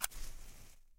声音效果 " 匹配照明
描述：普通火柴被点燃。